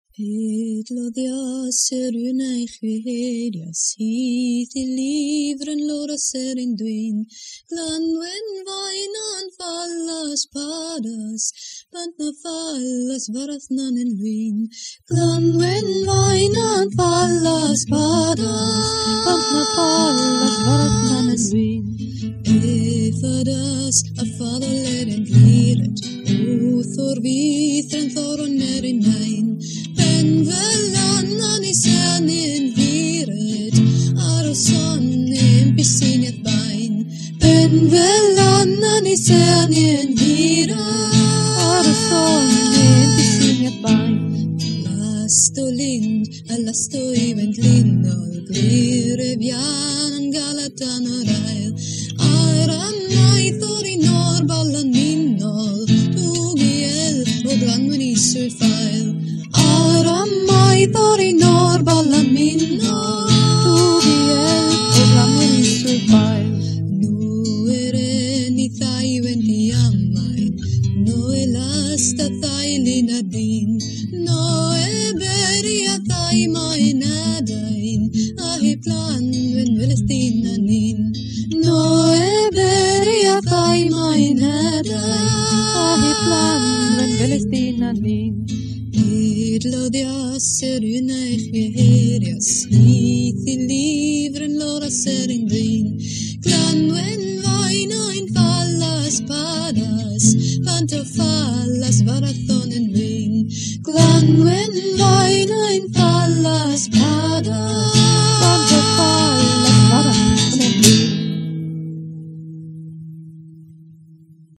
Edhellen 'lirnen - Sung Elvish